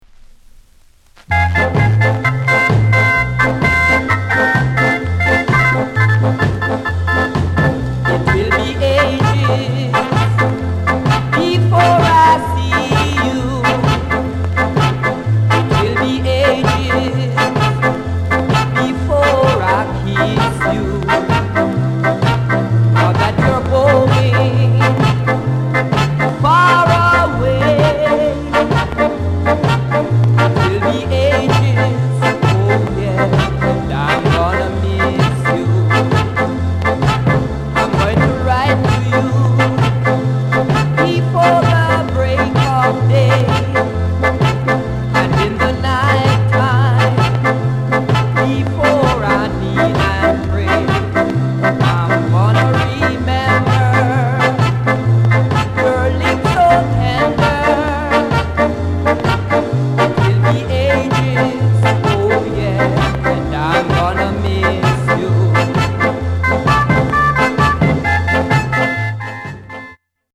SKA